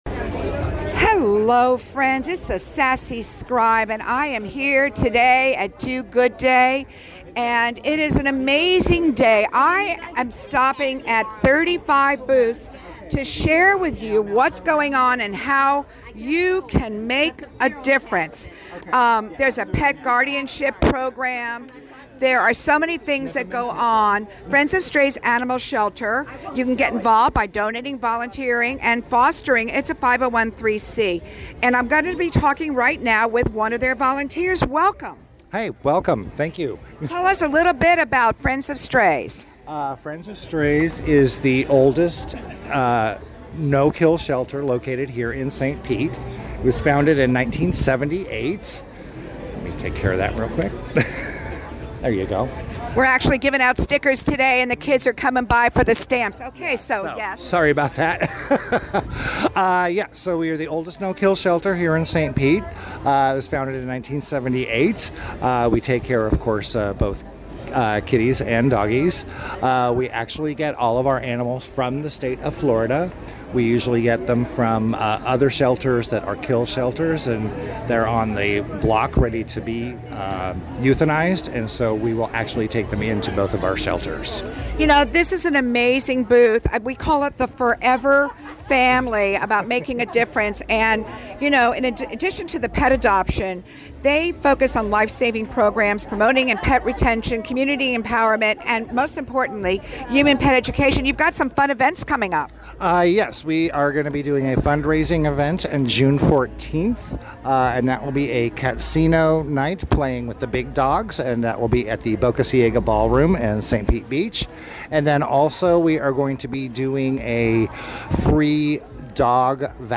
RadioStPete participated in Do Good Day at Fergs Sports Bar in downtown St. Pete March 29, interviewing some of the 35 non-profits sharing their mission and how you can volunteer and donate.